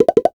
NOTIFICATION_Pop_01_mono.wav